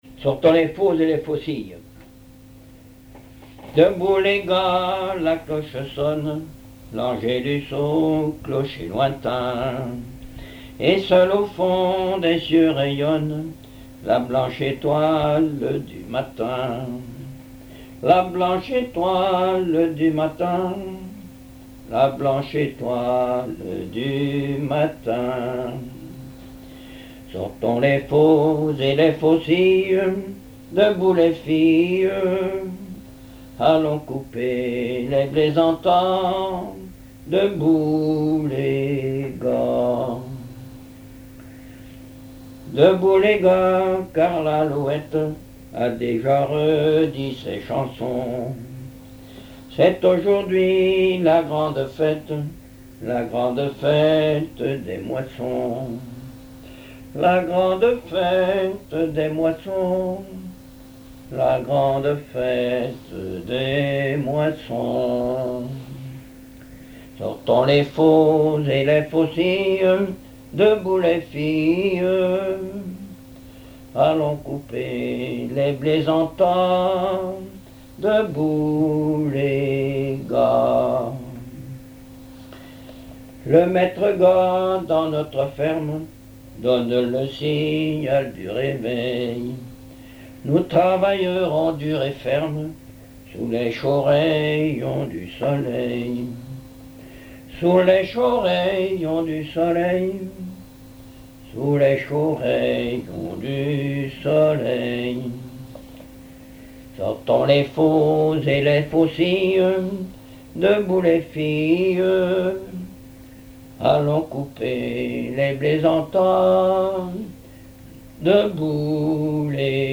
contes, récits et chansons populaires
Pièce musicale inédite